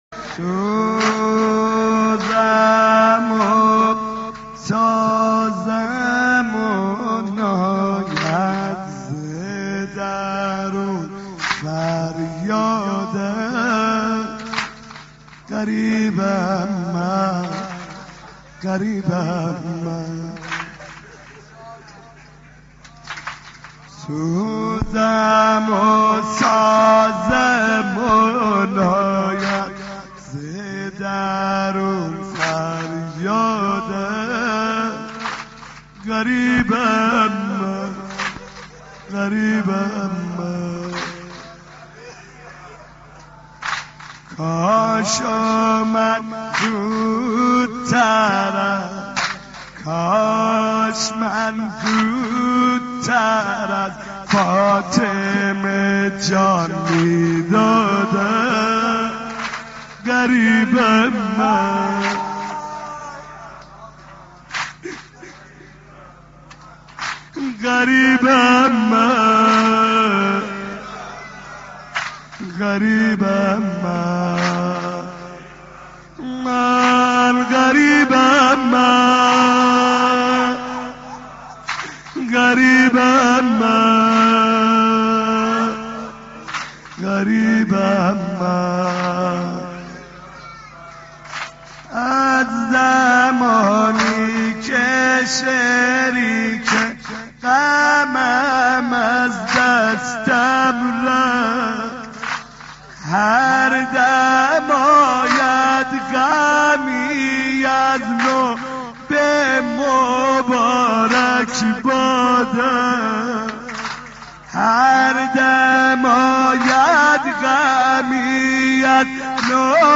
دانلود مداحی سوزم و سازم و ناید ز درون فریادم - دانلود ریمیکس و آهنگ جدید